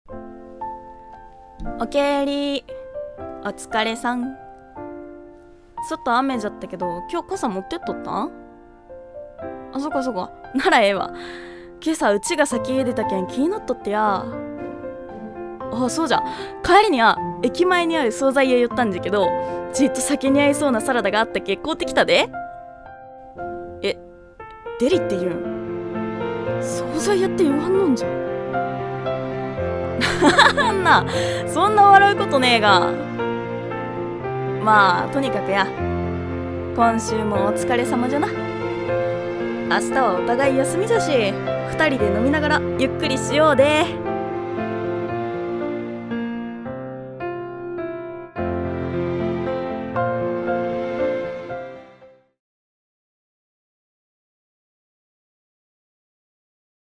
【方言推奨声劇】おかえりなさい